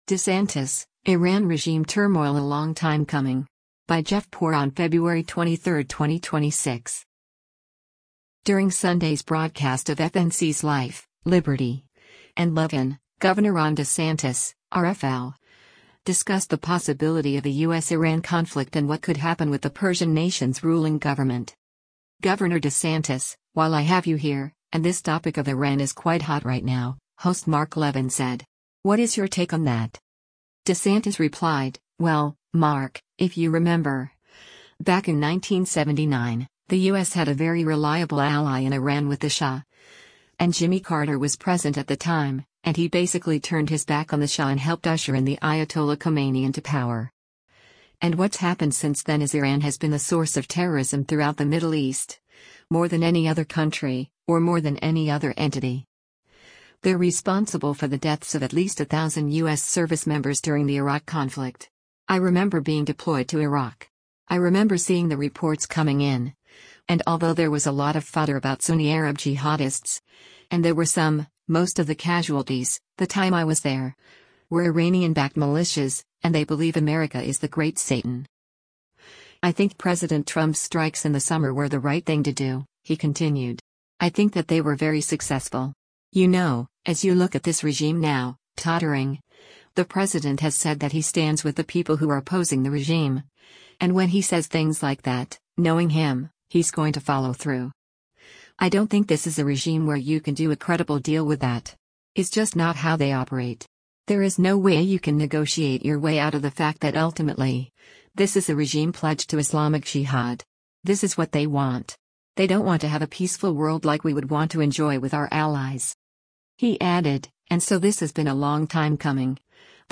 During Sunday’s broadcast of FNC’s “Life, Liberty & Levin,” Gov. Ron DeSantis (R-FL) discussed the possibility of a U.S.-Iran conflict and what could happen with the Persian nation’s ruling government.